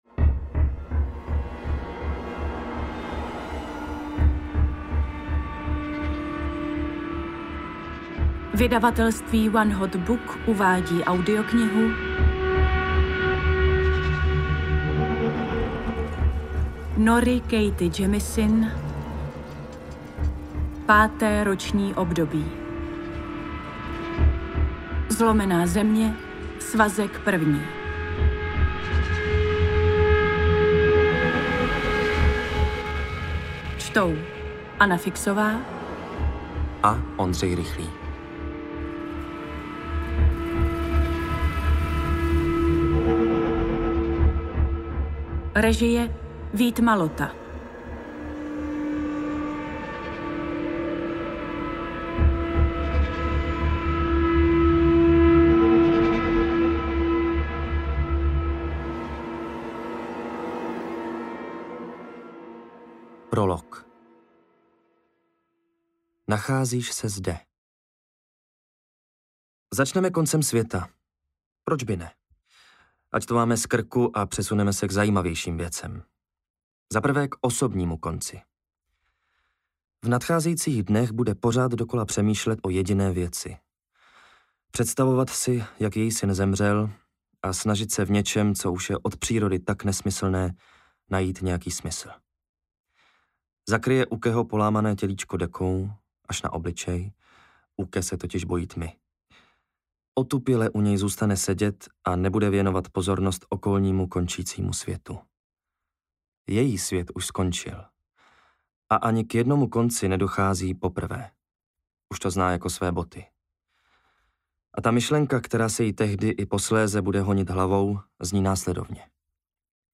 AudioKniha ke stažení, 26 x mp3, délka 15 hod. 3 min., velikost 822,0 MB, česky